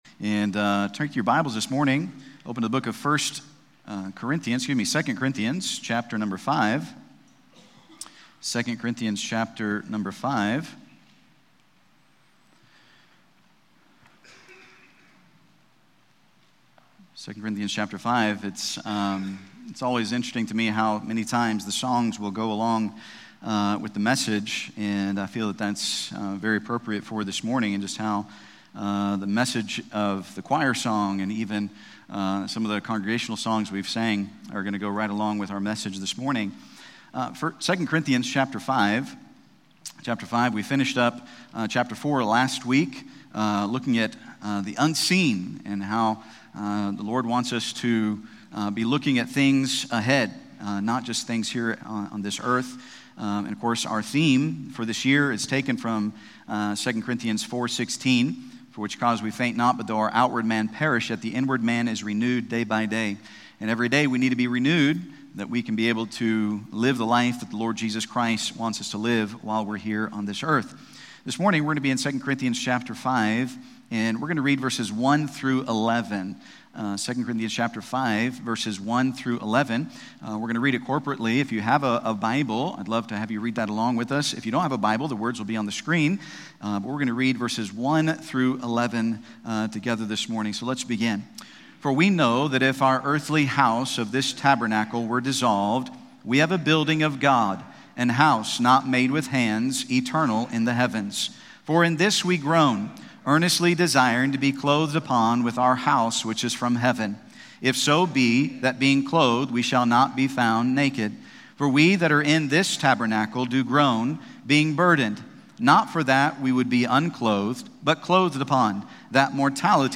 This sermon challenges you to focus on eternity, share the gospel, and live purposefully for Jesus, knowing you’ll one day stand before Him.